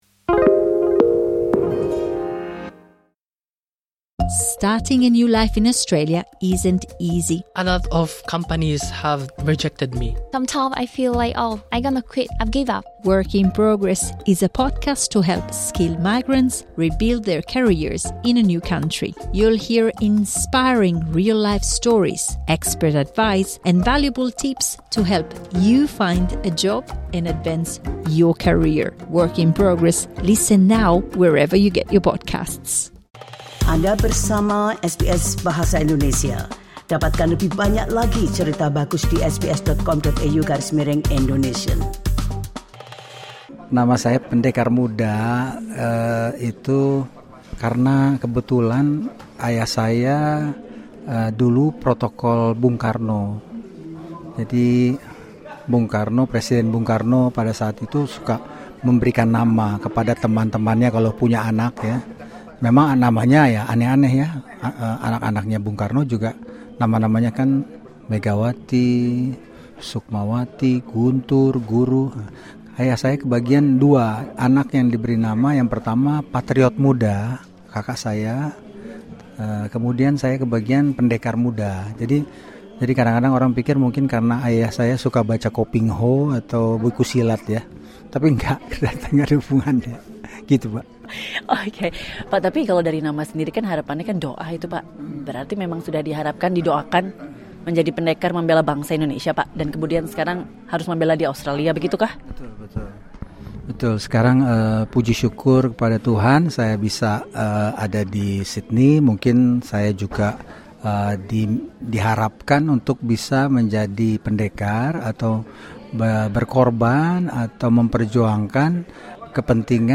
Dalam wawancara dengan SBS Indonesian, Konjen Sondakh menjelaskan apa saja yang menjadi sorotan dan misi perwakilan Indonesia dalam periode tugasnya ini. Beliau menggarisbawahi tentang keragaman diaspora Indonesia di Sydney dan perhatian terkait pemegang Working Holiday Visa (WHV) Australia.